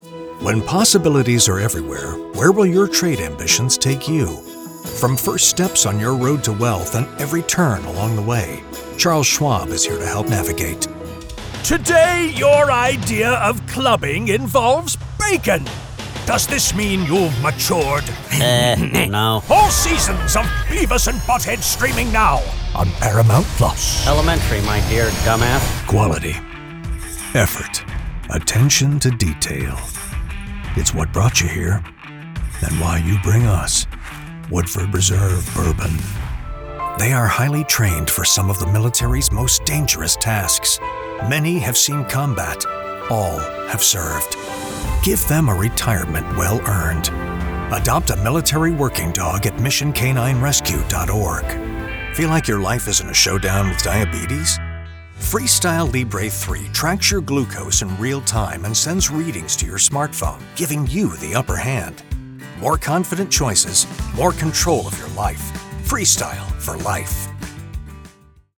Inglês (dos Estados Unidos)
Articular
Conversacional
Autoritário